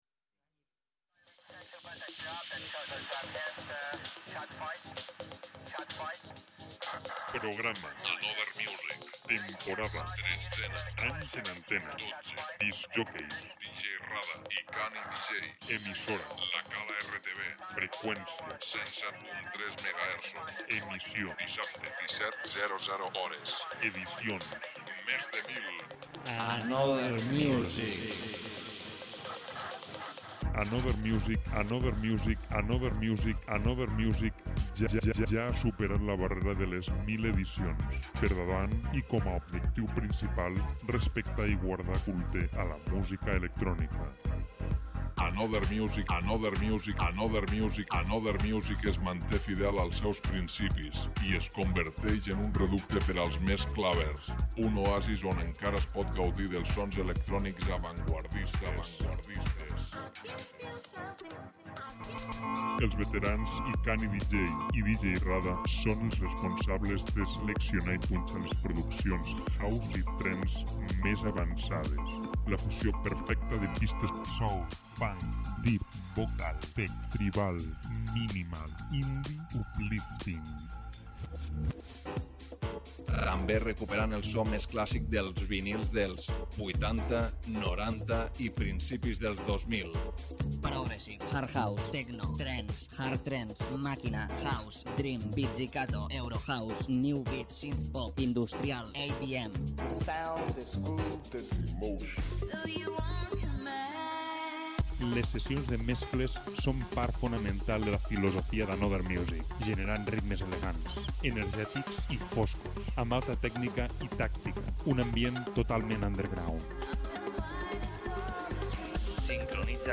creant un espai underground i elegant.